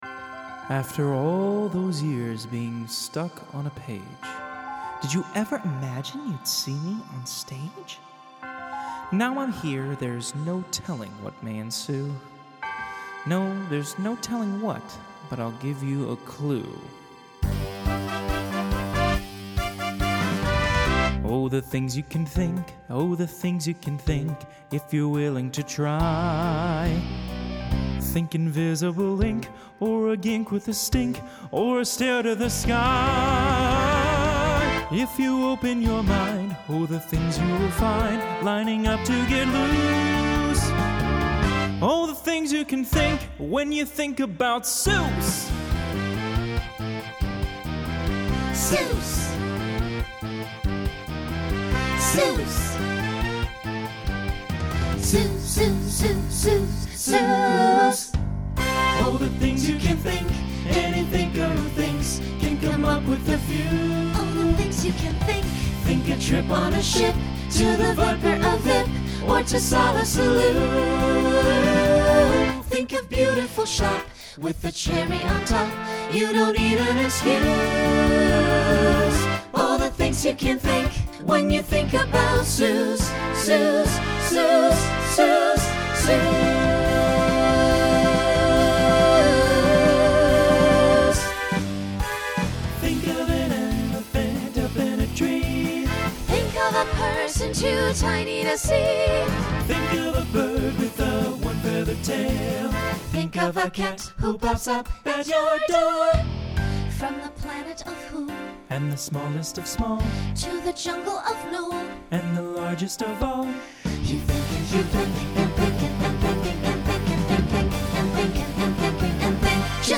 Genre Broadway/Film
Story/Theme Voicing SATB